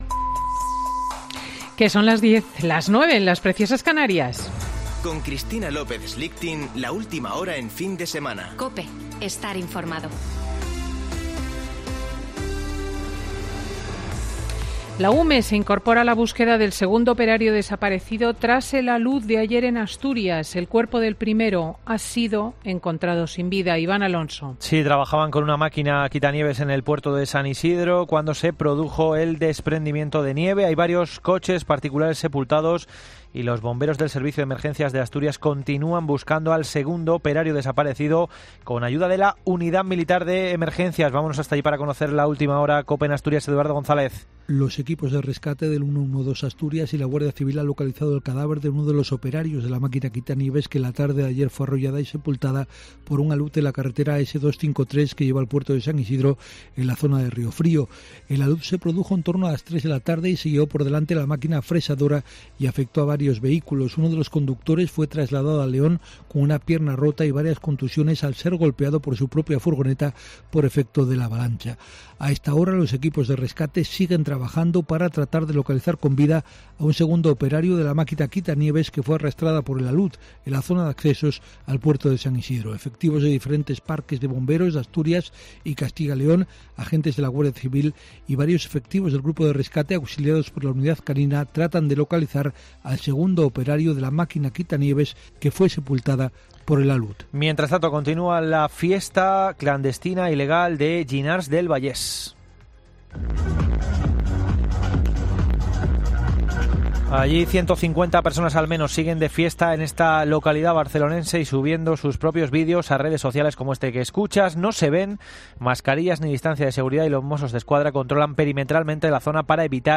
Boletín de noticias COPE del 2 de enero de 2021 a las 10.00 horas